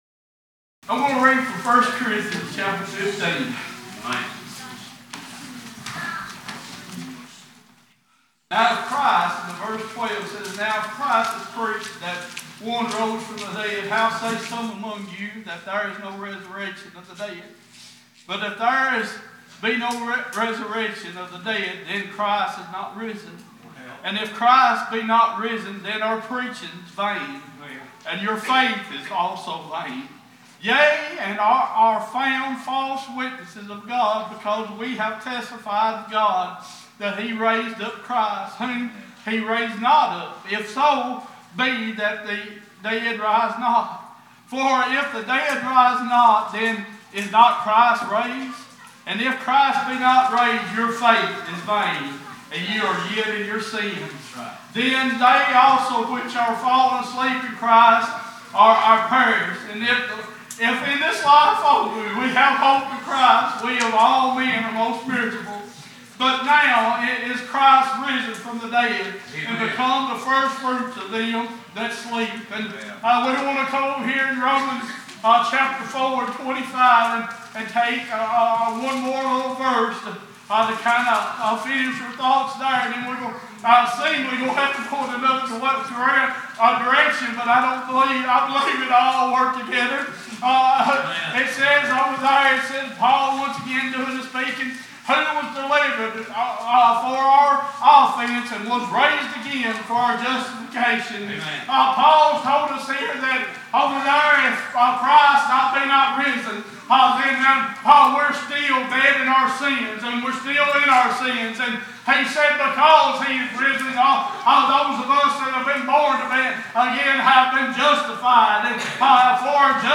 Series: Sunday Morning Passage: 1 Corinthians 15:12-20, Romans 4:25, John 12 Service Type: Worship « Macedonian Call